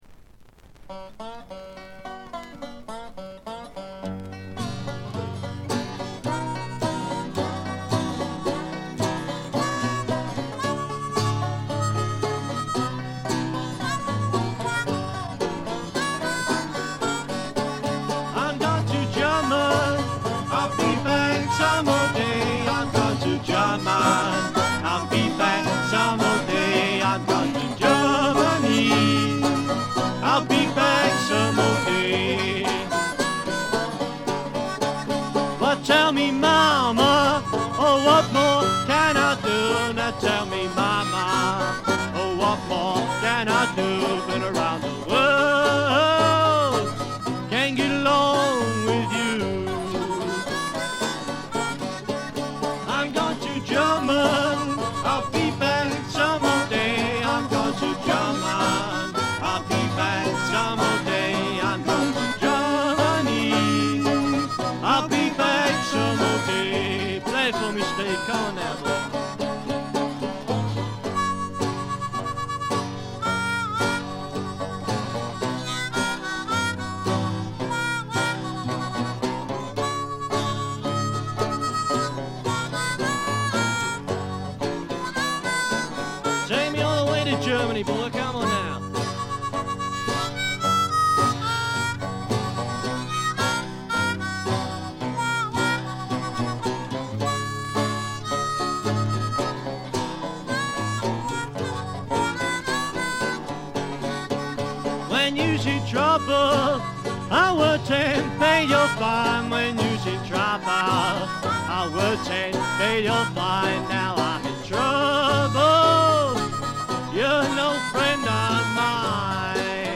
軽微なバックグラウンドノイズのみ。
試聴曲は現品からの取り込み音源です。
lead vocals, kazoo, guitar, banjo, bass drum
washboard, harmonica, jug, hoot